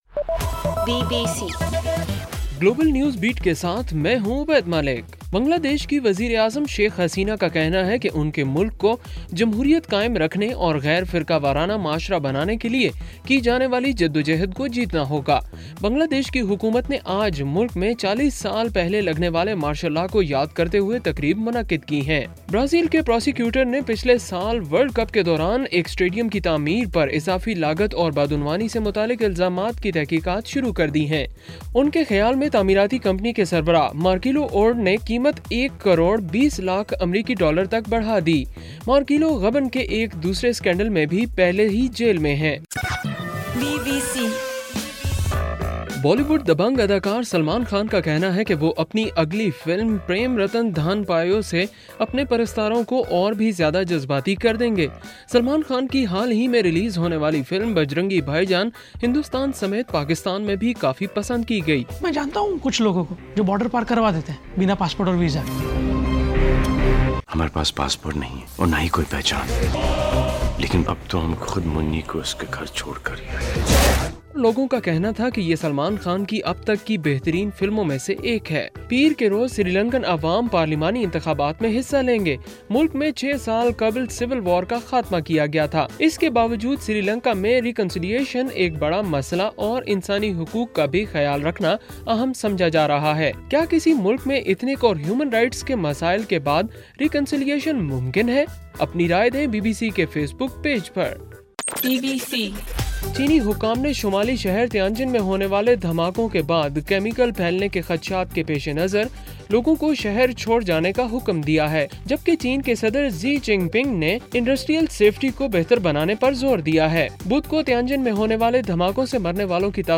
اگست 15: رات 12 بجے کا گلوبل نیوز بیٹ بُلیٹن